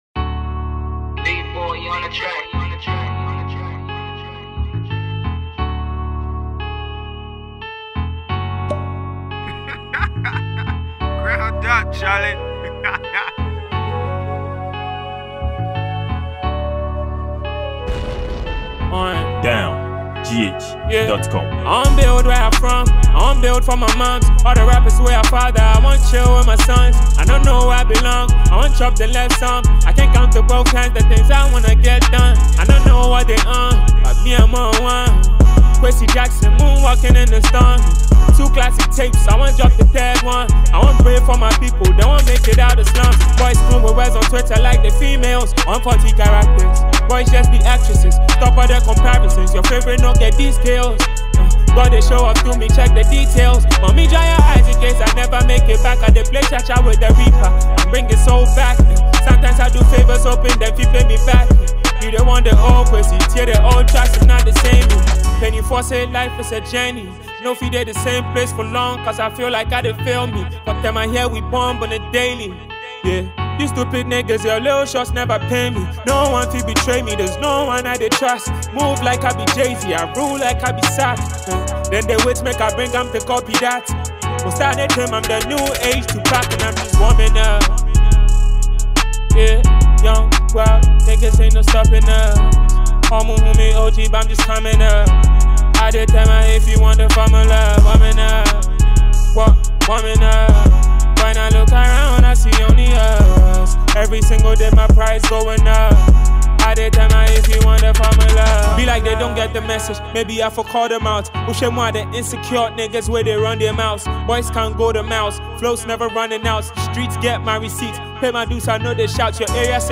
Ghanaian rapper and well-know artist